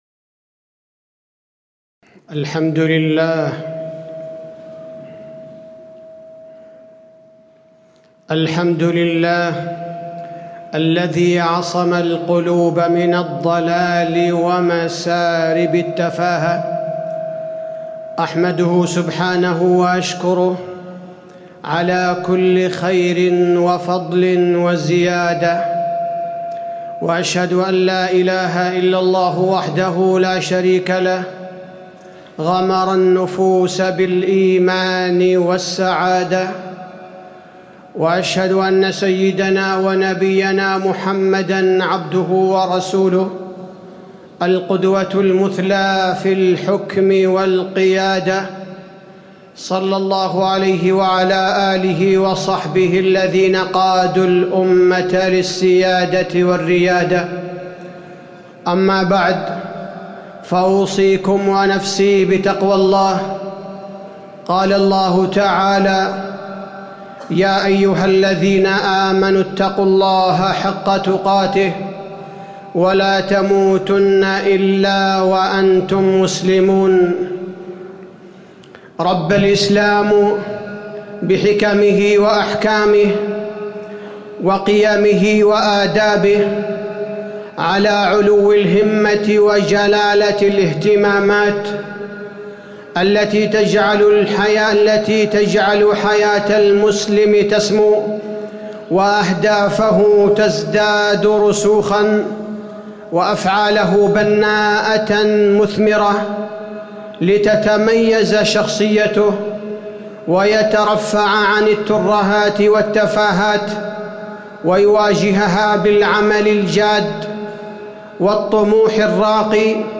تاريخ النشر ٢٧ محرم ١٤٣٨ هـ المكان: المسجد النبوي الشيخ: فضيلة الشيخ عبدالباري الثبيتي فضيلة الشيخ عبدالباري الثبيتي علو الهمة